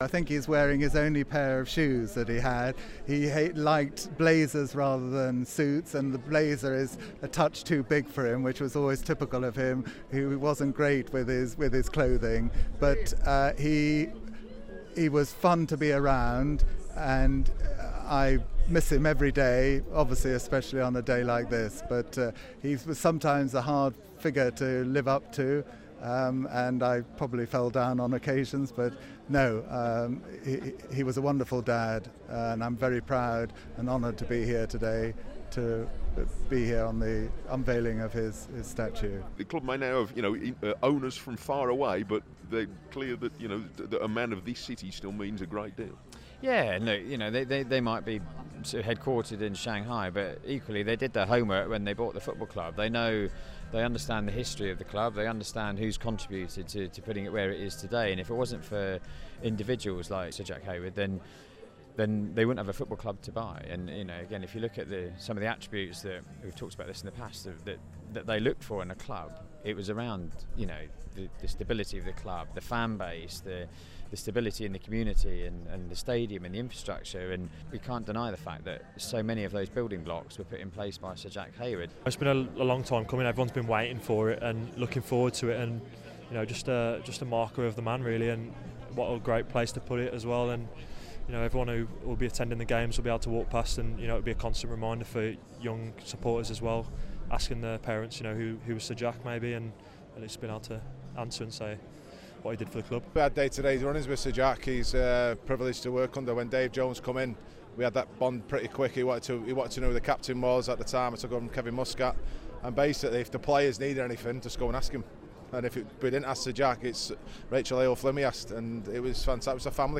He was remembered today by hundreds of supporters and invited guests who gathered to see the unveiling.